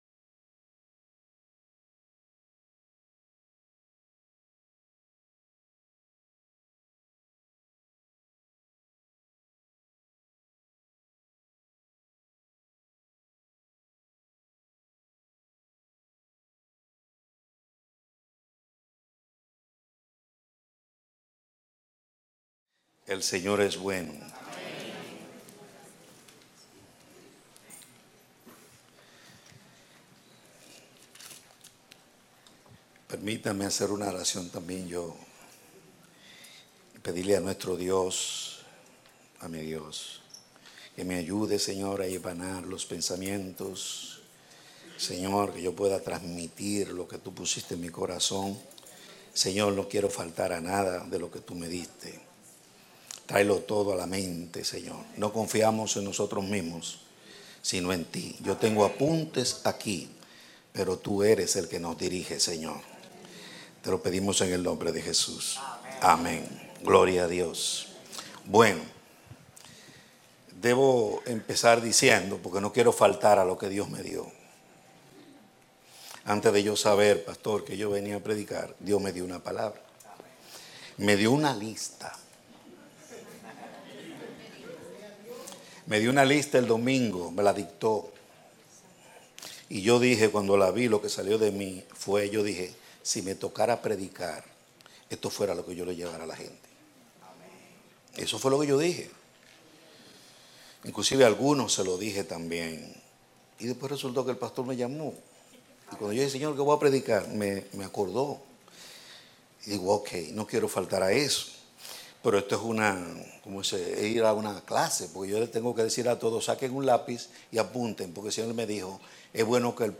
Predicado Domingo Enero 22, 2017